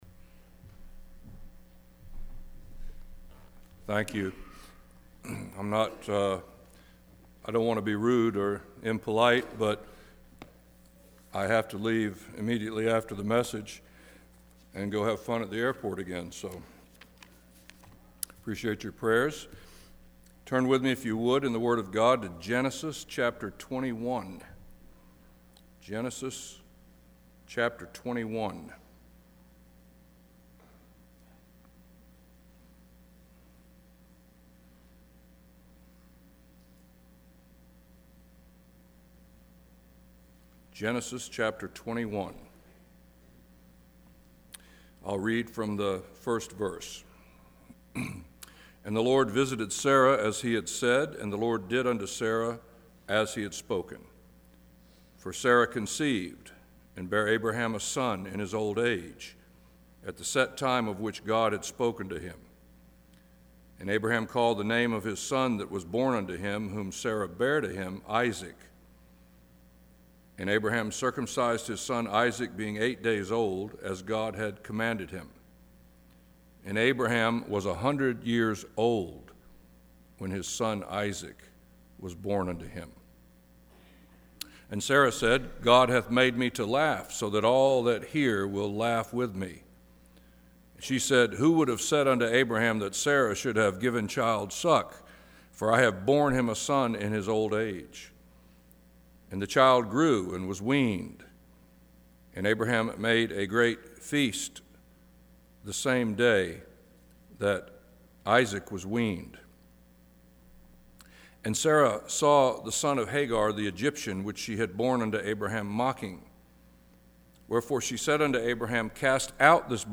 Sermons - Martinez Bible Chapel - Page 2